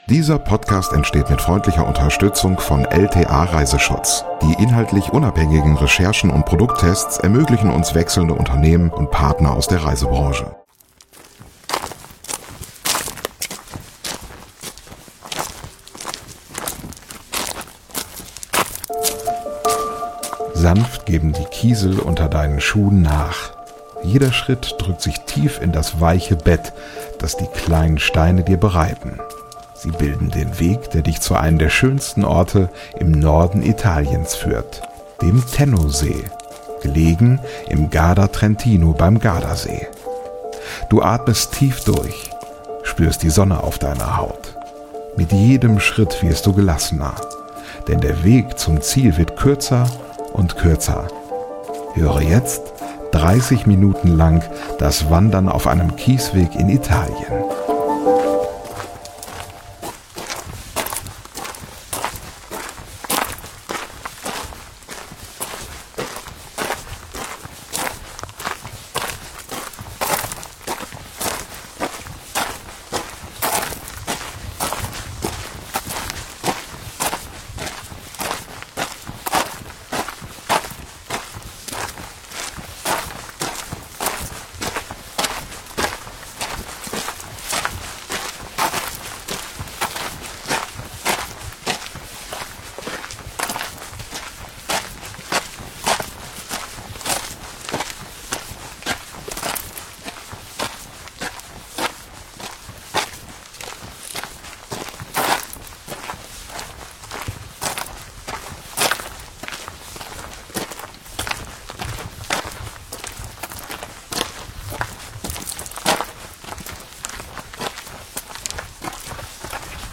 ASMR Auf dem Weg zum Tennosee in Italien: Ambient 3D-Sound zum Einschlafen ~ Lieblingsreisen - Mikroabenteuer und die weite Welt Podcast
Höre jetzt 30 Minuten lang das Wandern auf einem Kiesweg in Italien.